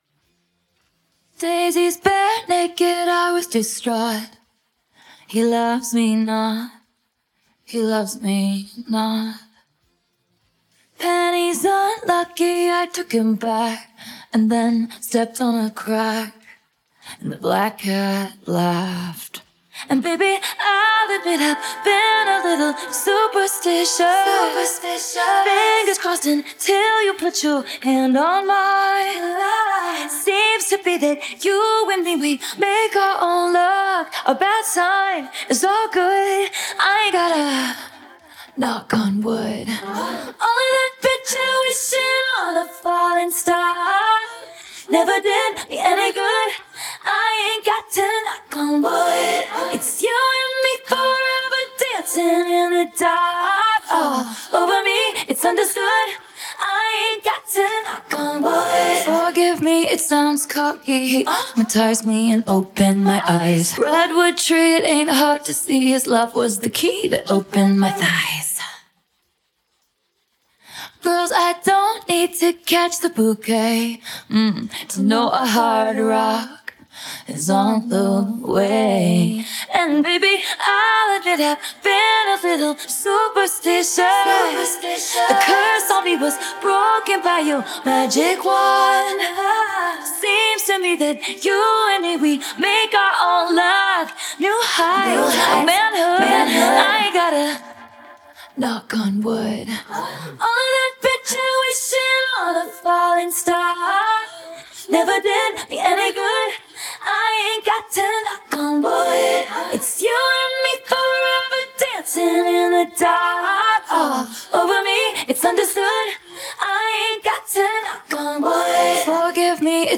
hear vocals.